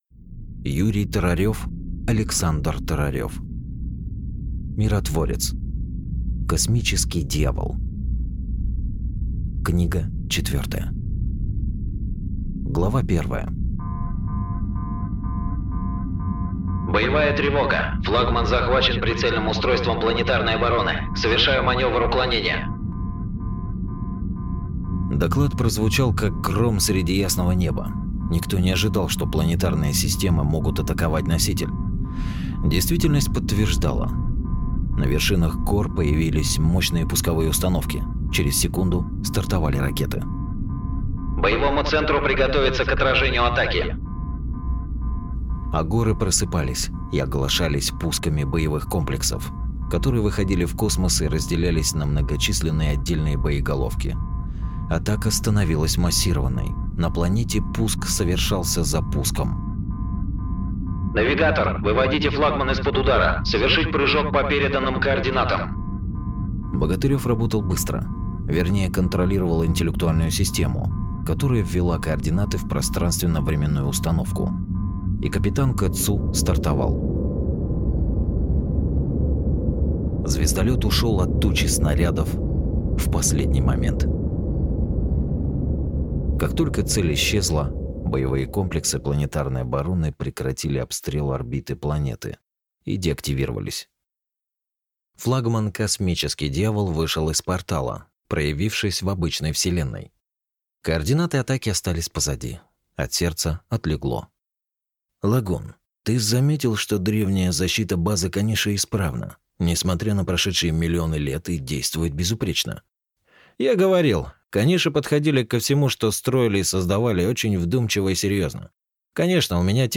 Аудиокнига Миротворец. Космический дьявол. Книга четвертая | Библиотека аудиокниг